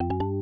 notification.wav